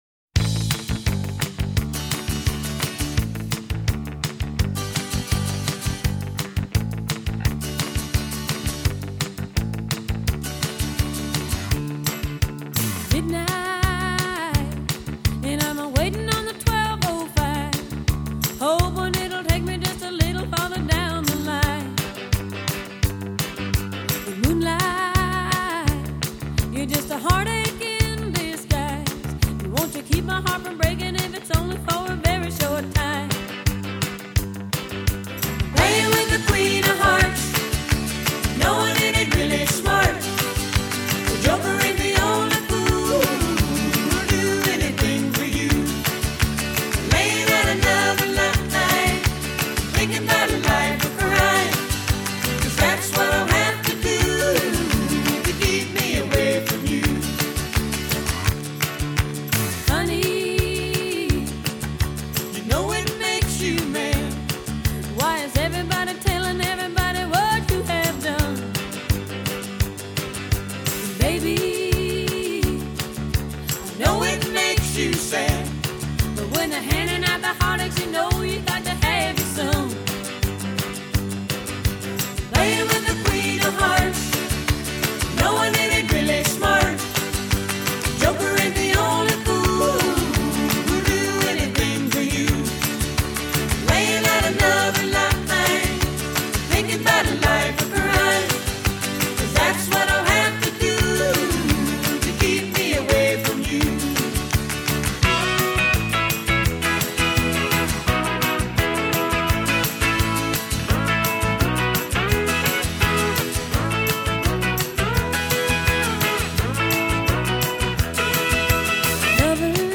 오리지날보다 훨씬 더 부드럽고 매끈한 어레인지로 편곡돼